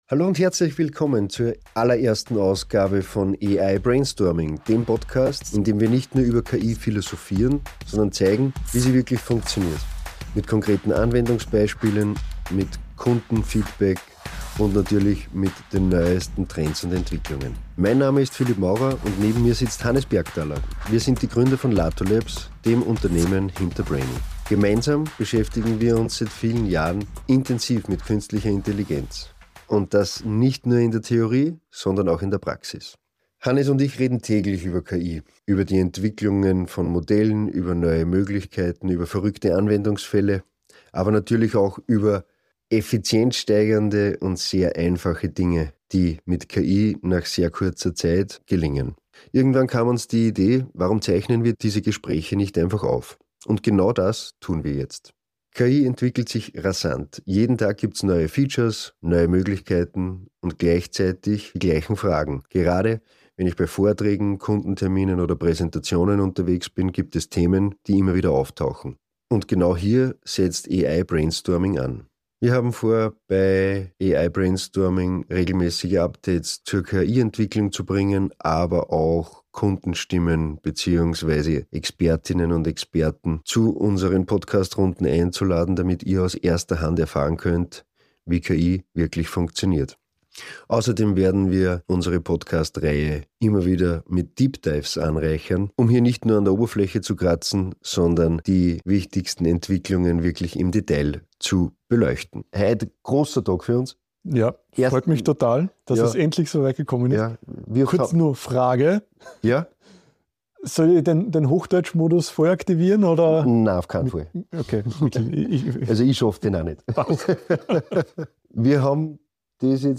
Jetzt zeichnen sie diese Gespräche auf, um ihre Erkenntnisse mit euch zu teilen. Themen dieser Folge KI-Modelle im Vergleich: Gemini, Anthropic, ChatGPT, Perplexity & Grok – welches Modell für welchen Zweck? Token Size & Context Windows: Wie hat sich die Verarbeitungskapazität entwickelt und was bedeutet das praktisch?